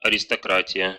Ääntäminen
Synonyymit свет знать Ääntäminen Tuntematon aksentti: IPA: /ərʲɪstɐˈkratʲɪjə/ Haettu sana löytyi näillä lähdekielillä: venäjä Käännös 1. nobiltà {f} 2. aristocrazia {f} Translitterointi: aristokratija.